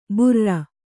♪ burra